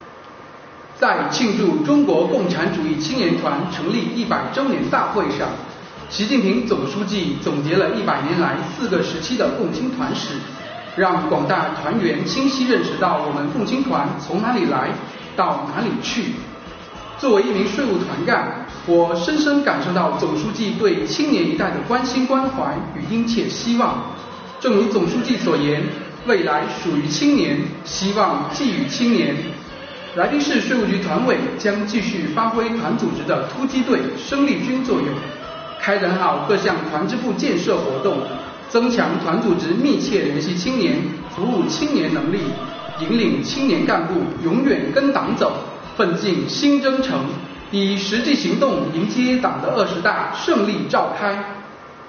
建团百年，听听来宾税务青年的心里话......